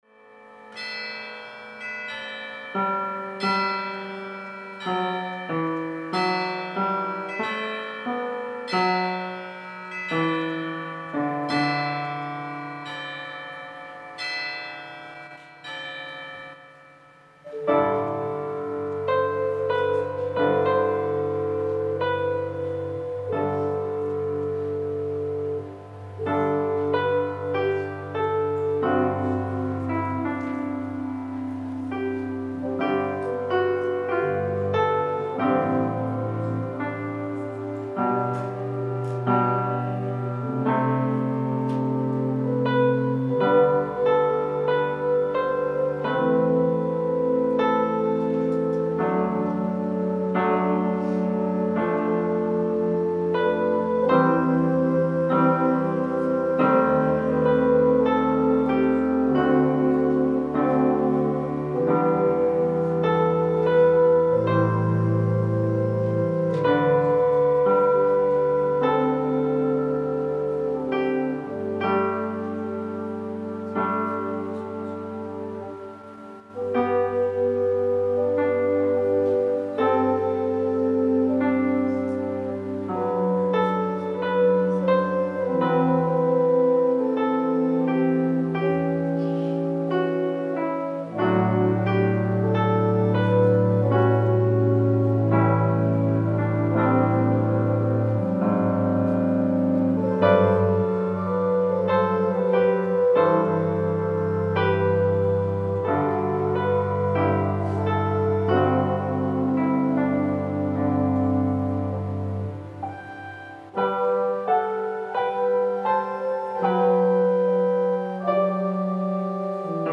Musical Portions of Christmas Eve 2024 – Pollock Memorial Presbyterian Church
Prelude
Choir Anthem: “He Is Born” Alto Saxophone
Bass Clarinet